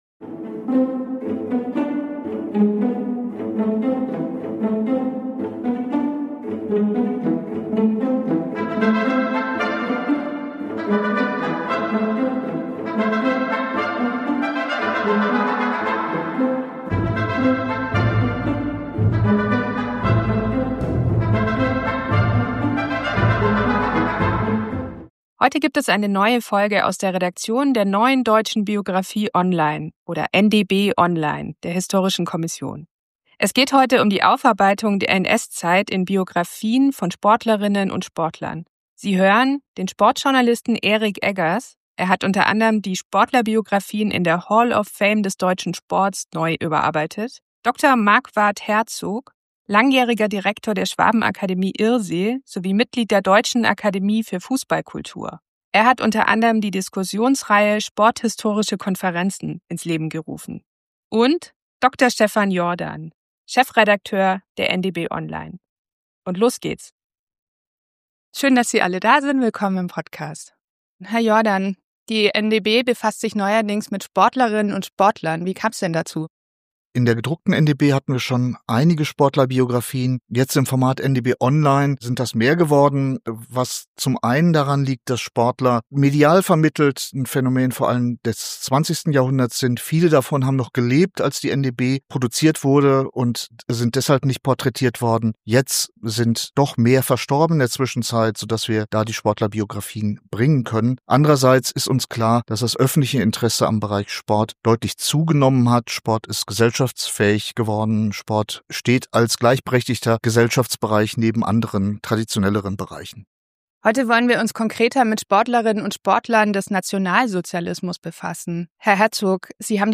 Die Aufarbeitung der NS-Zeit in Biografien von Sportlerinnen und Sportlern. Ein sporthistorisches Gespräch